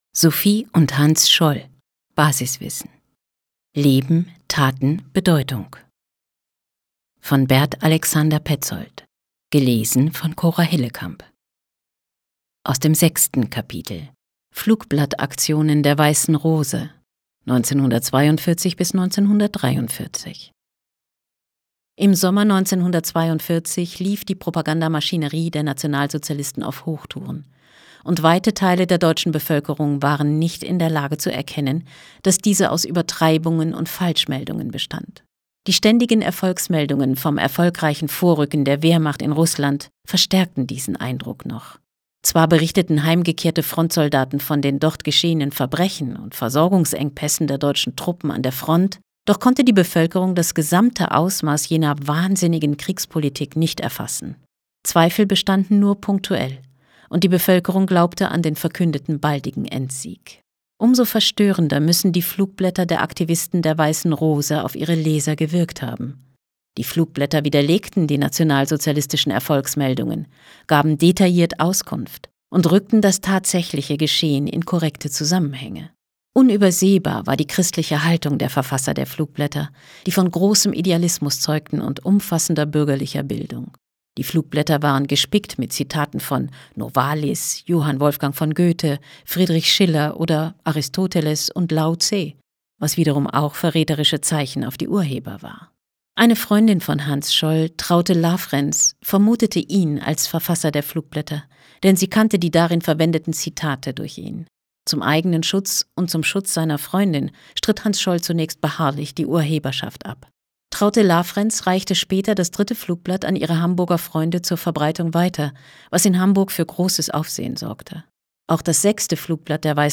Hörbuch